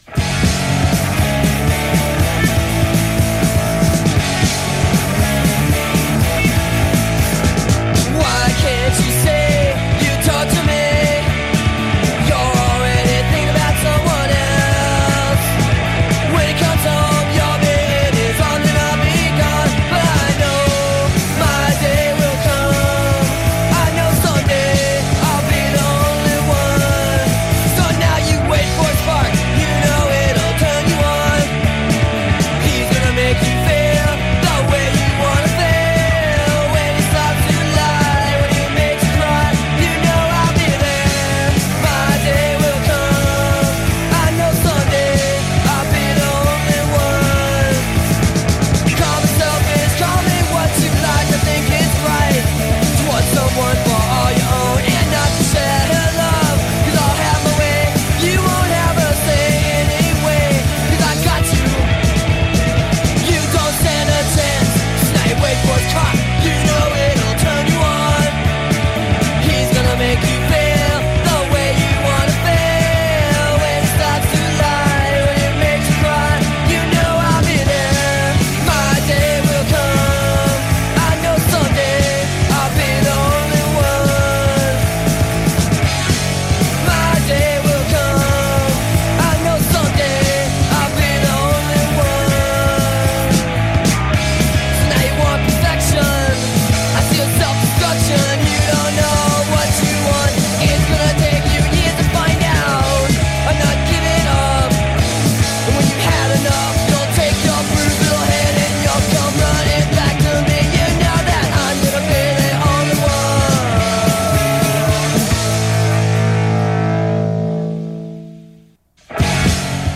Intervista a Milo Aukerman, Descendents | Rocktrotter | Radio Città Aperta